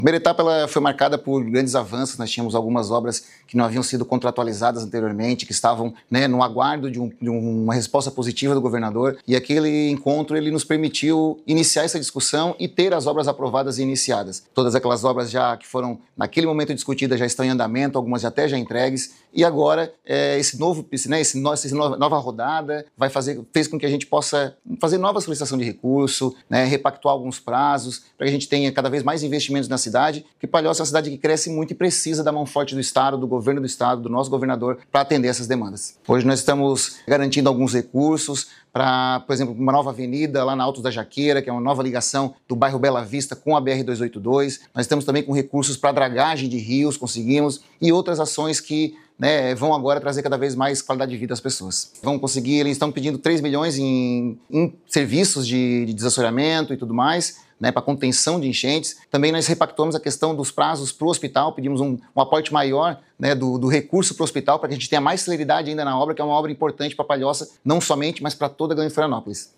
Sonora – SC Levada a sério: prefeito de Palhoça destaca pedido para obras de infraestrutura e contenção de enchentes